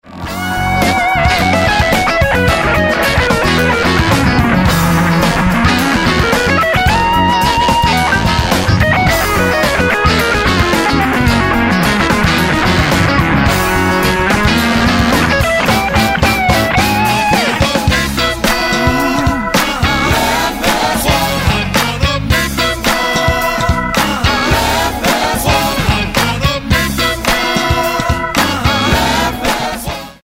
acoustic & electric guitars
drums
keyboards, lyricon, tenor saxophone
electric bass, vocals